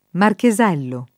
[ marke @$ llo ]